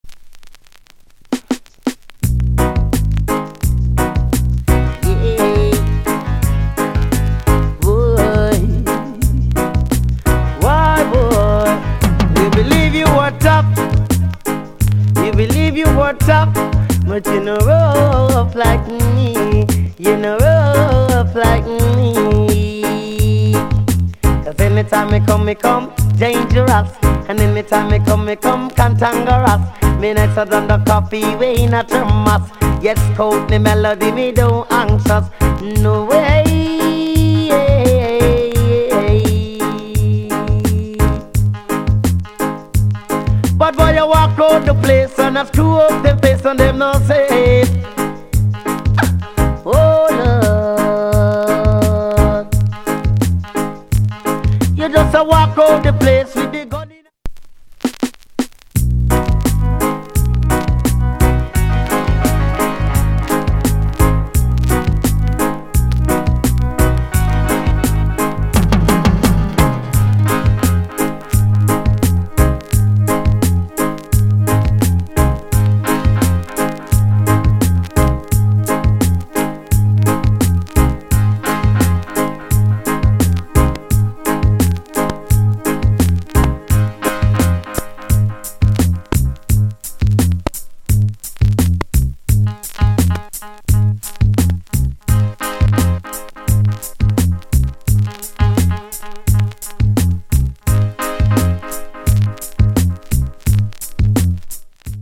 80's Dance Hall Classic